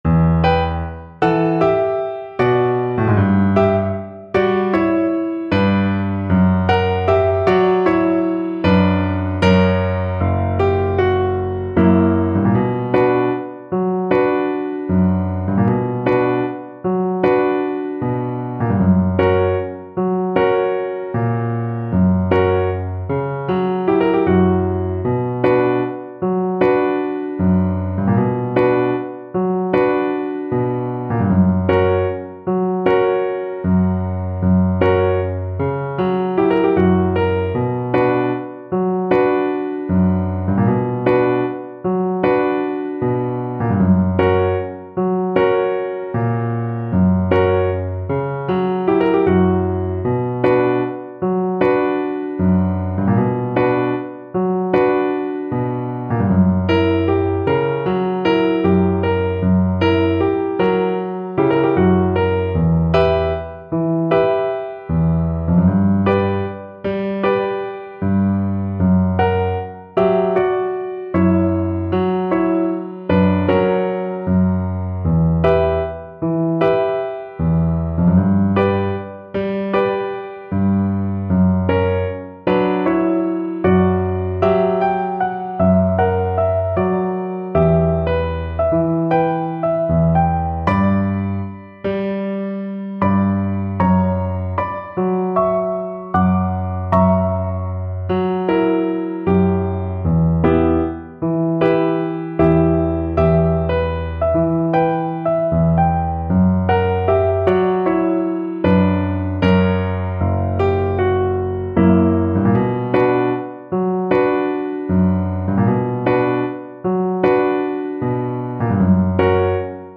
Violin version
D major (Sounding Pitch) (View more D major Music for Violin )
Traditional (View more Traditional Violin Music)